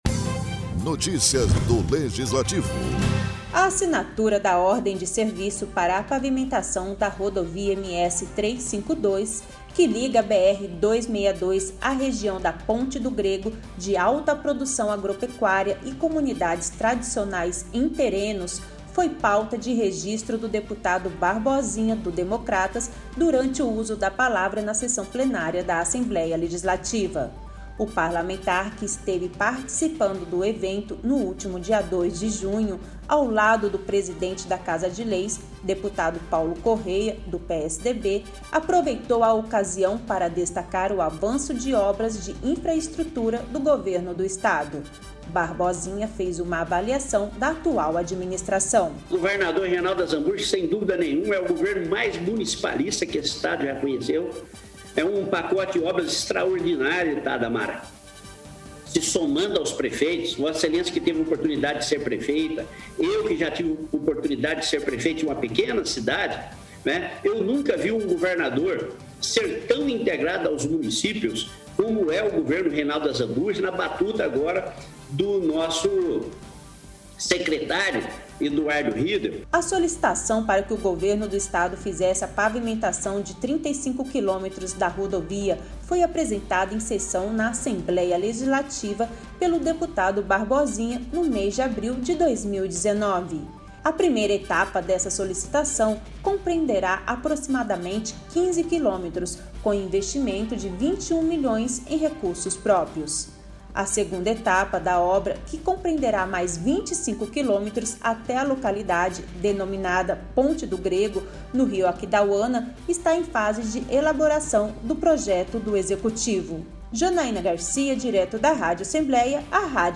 O deputado Barbosinha (Democratas), fez uso da palavra na sessão plenária desta terça-feira (8) para registrar os avanços das obras de infraestrutura do Estado.